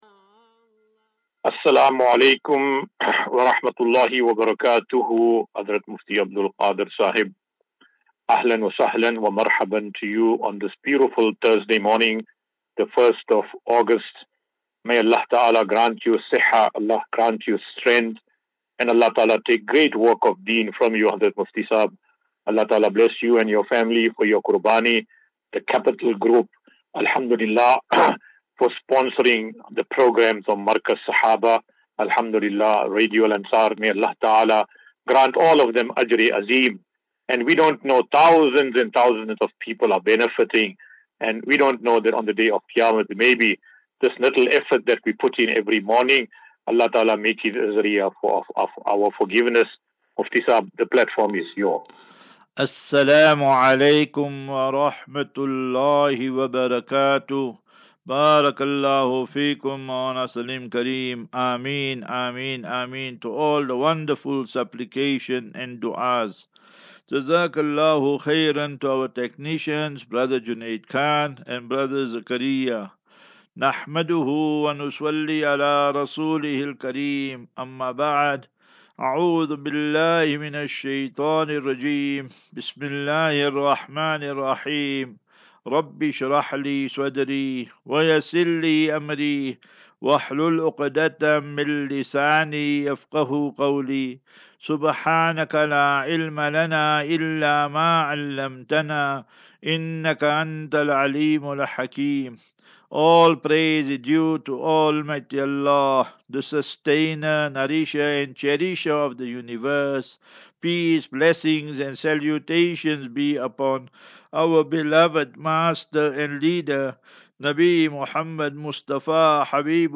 Assafinatu - Illal - Jannah. QnA.